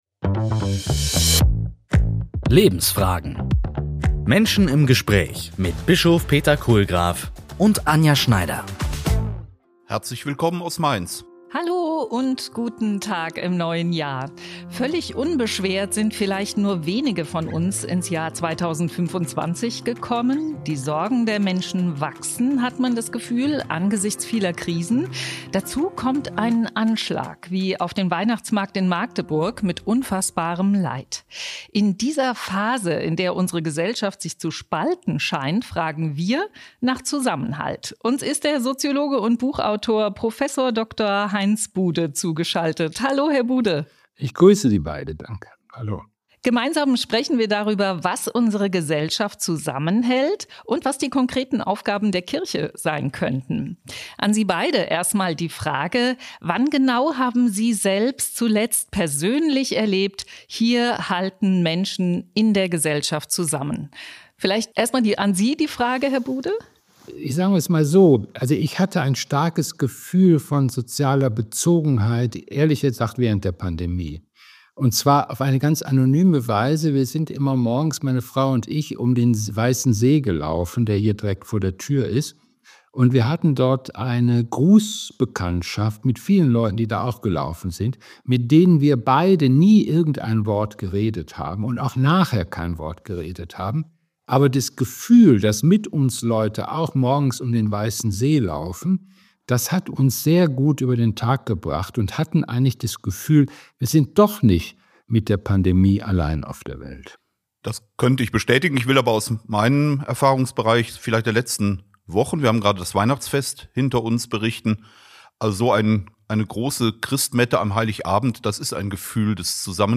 Zu Gast: Heinz Bude Thema: Was hält unsere Gesellschaft zusammen?